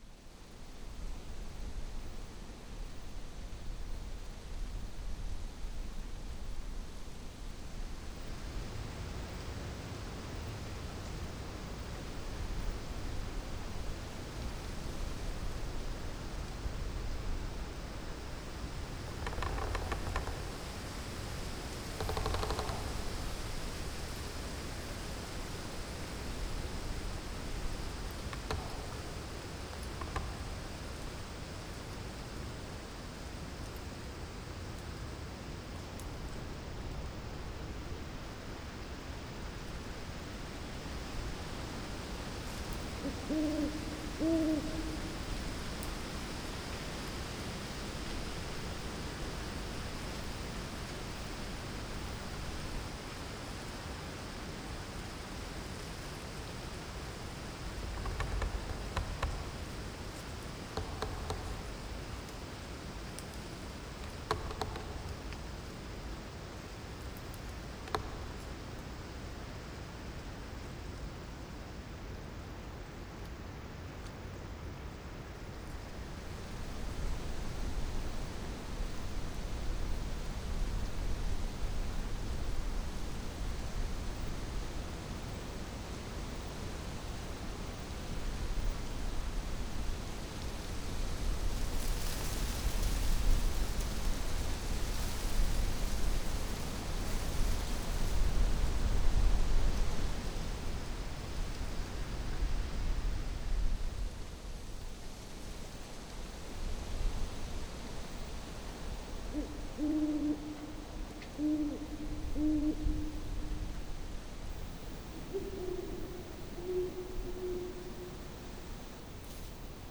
ForestAtmos_Night_01.wav